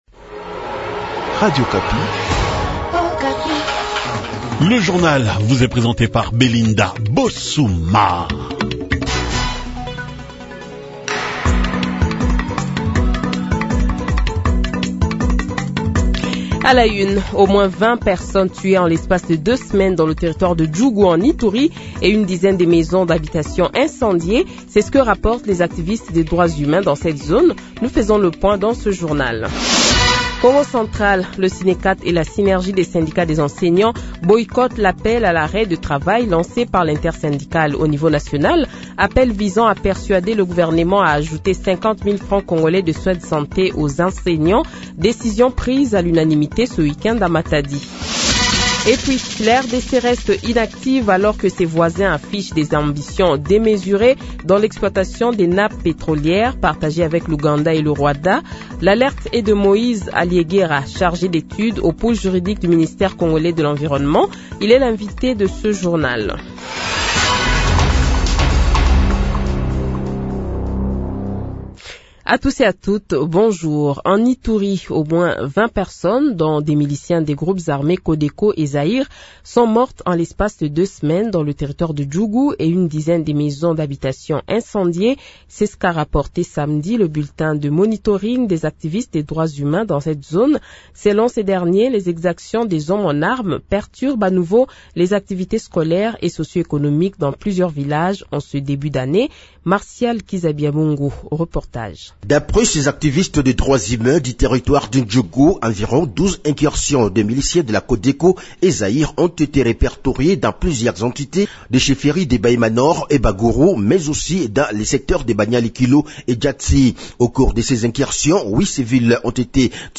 Journal Francais Matin
Le Journal de 8h, 20 Janvier 2025 :